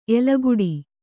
♪ elaguḍi